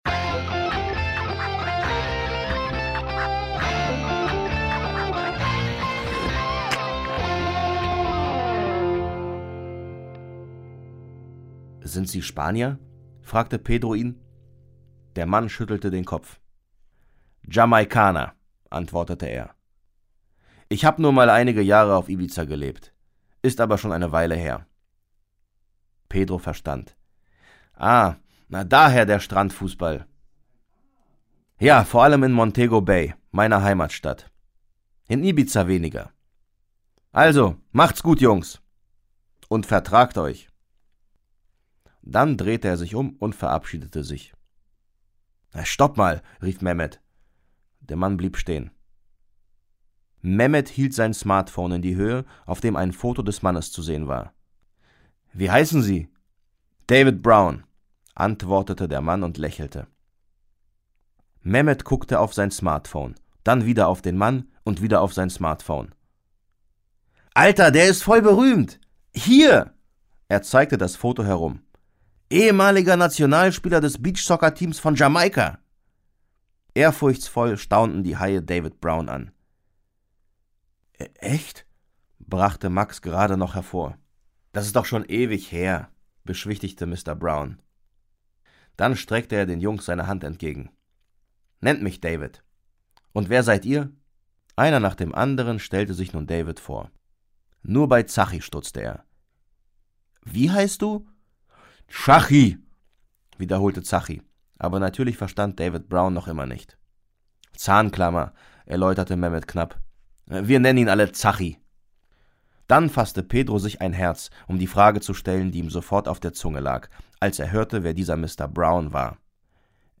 Schlagworte Fußball; Kinder-/Jugendliteratur • Hörbuch; Lesung für Kinder/Jugendliche • Kickers • Kinder/Jugendliche: Sportromane • Straßenfußball • Trainer